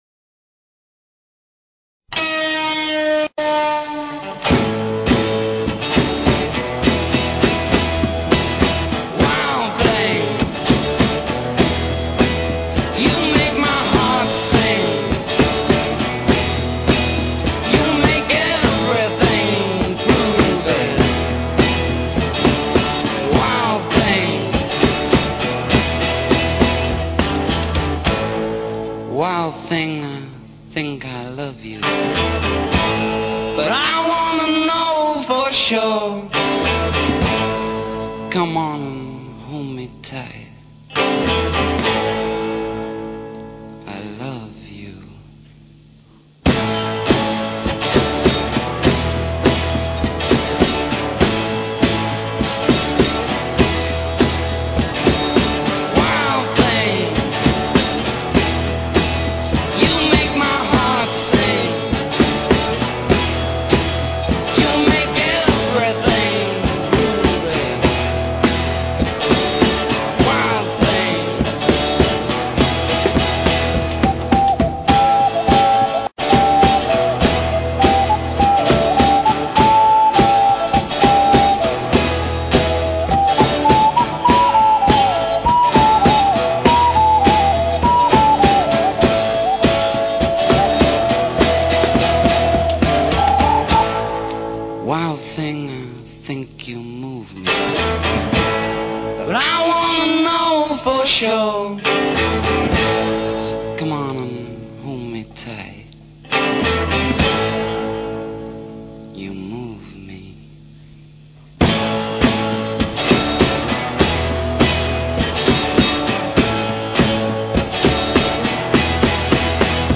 Talk Show Episode, Audio Podcast, Tallkats Psychic 101 and Courtesy of BBS Radio on , show guests , about , categorized as